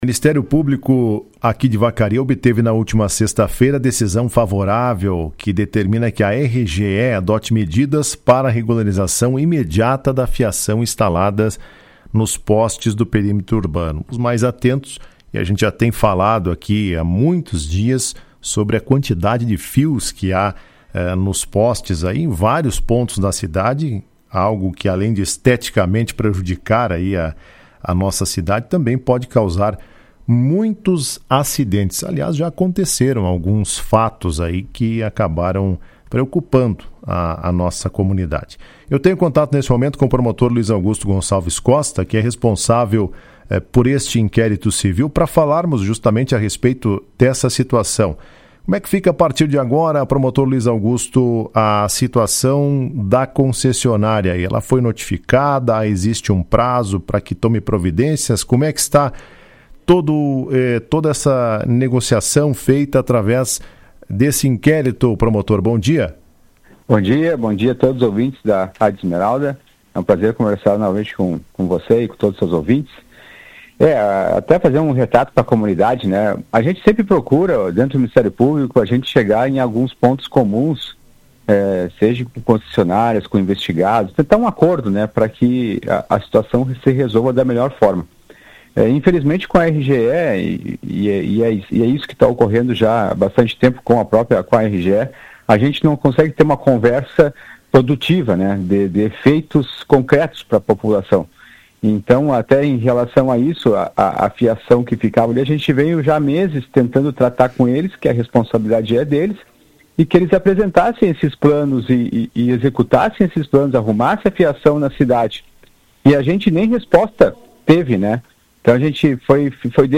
Em entrevista à Rádio Esmeralda, o promotor de Justiça Luis Augusto Gonçalves Costa, responsável pelo inquérito civil, disse que a medida se mostrou necessária diante do risco apresentado à população de Vacaria.